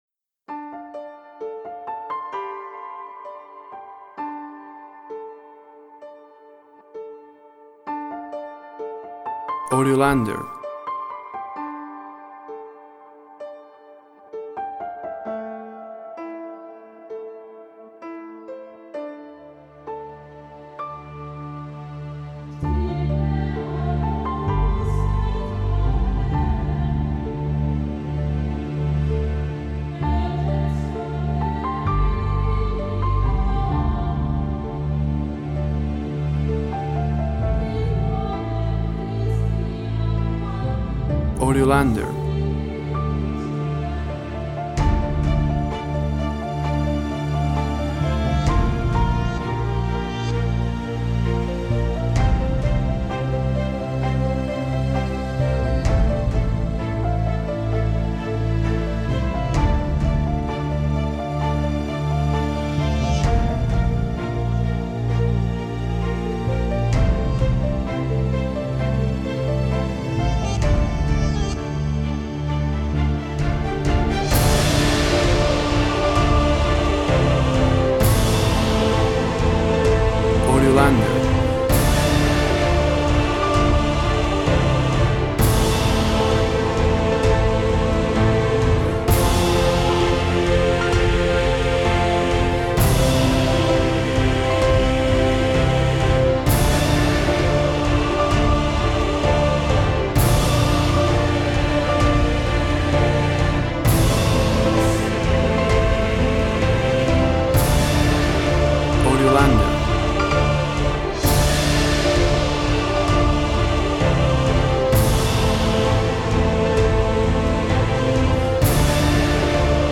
Orchestral sounds of epic and fictional cinema.
Tempo (BPM) 66